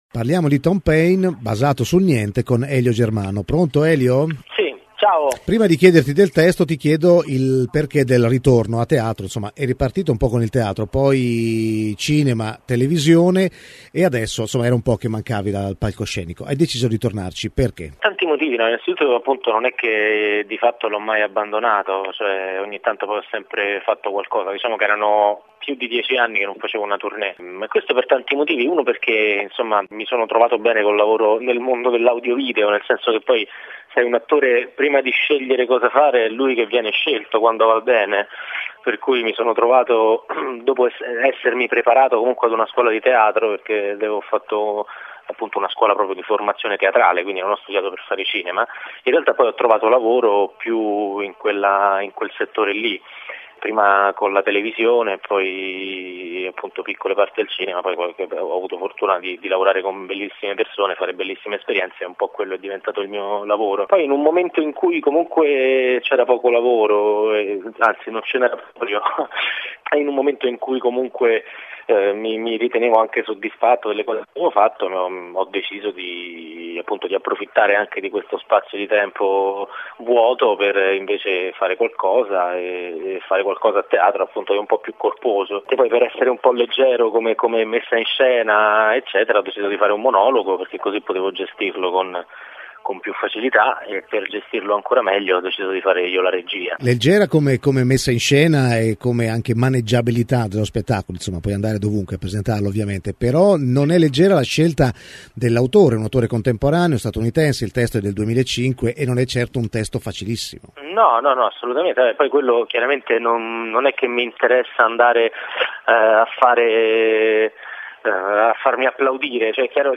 Intervista a Elio Germano | Radio Città del Capo
Elio Germano è stato ospite a Humus per raccontare lo spettacolo Thom Pain, scritto nel 2005 dall’autore americano Will Eno. L’attore ci ha parlato del suo ritorno al teatro dopo alcuni anni di assenza.